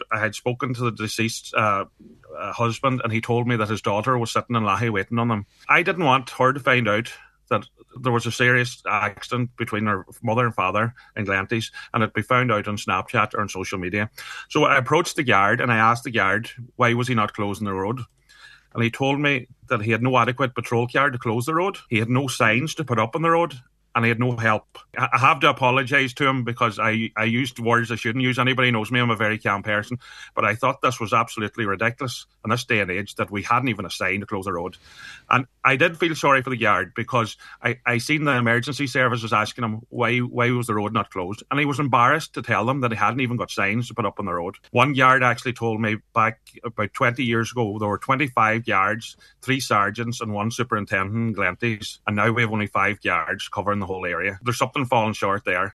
The lady’s husband was injured, and on today’s Nine til Noon Show Cllr Boyle recalled how the bereaved man expressed concern that their daughter was waiting for them.